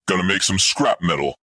I optimized the streamlined configuration of the previously produced Lasher Tank and equipped it with a new Lasher Tank voice, which is generally consistent with the tone of the original dialogue.
Voicelines sound a little bit AI, but good.